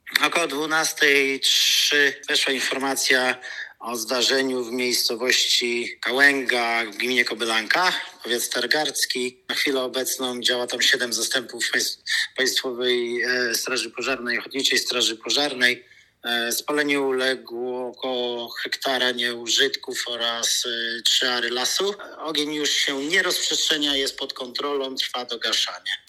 dla Twojego Radia mówił